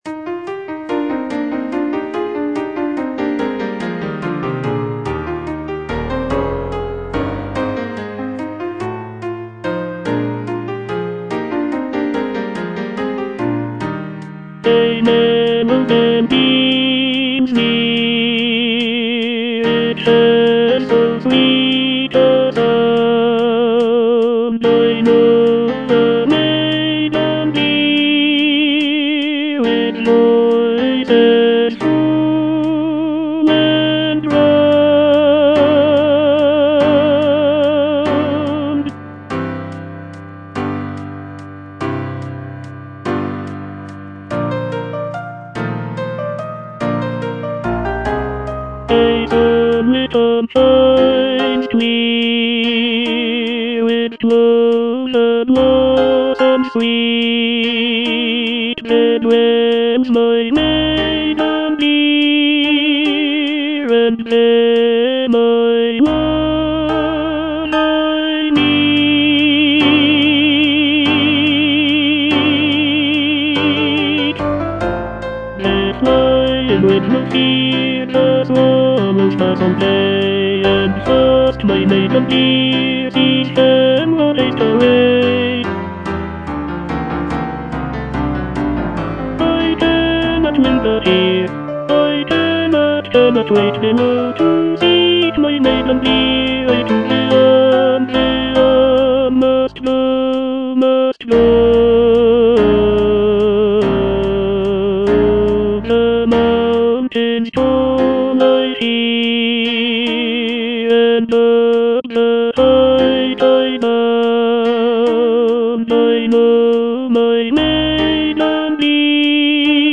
E. ELGAR - FROM THE BAVARIAN HIGHLANDS On the alm (tenor II) (Voice with metronome) Ads stop: auto-stop